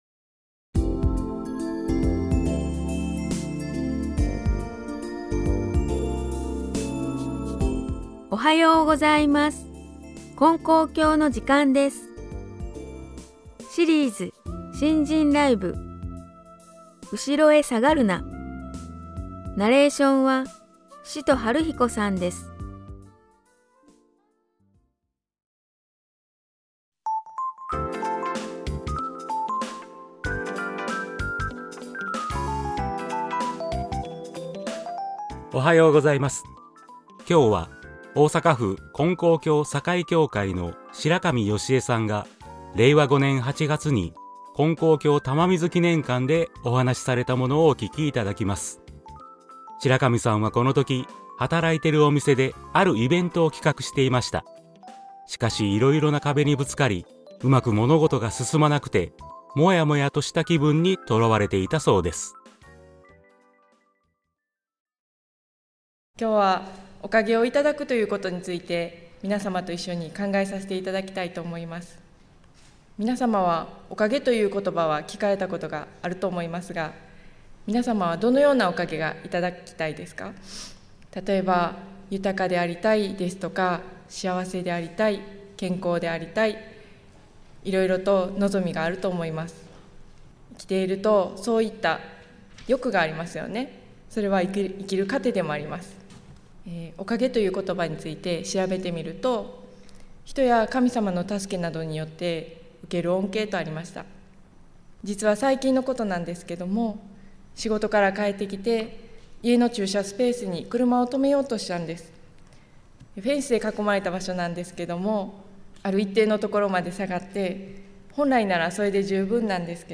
●信心ライブ